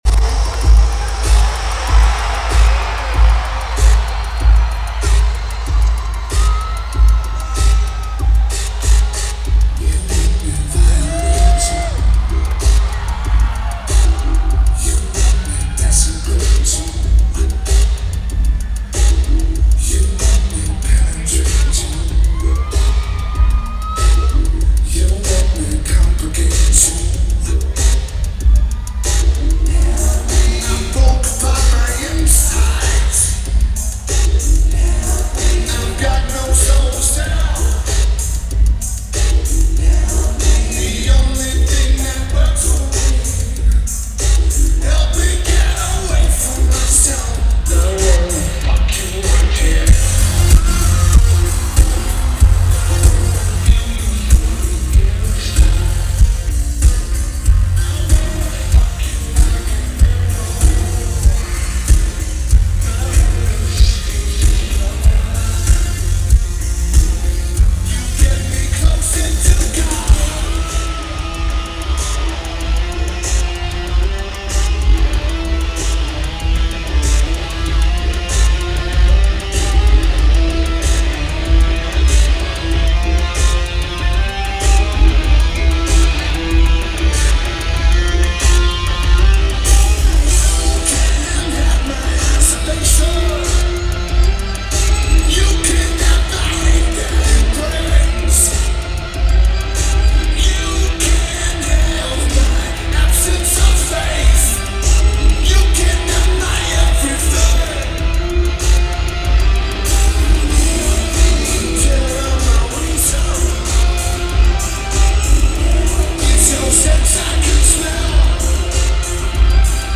Oracle Arena
Lineage: Audio - AUD (CA-11 + CA-BB + Sony MZ-R70)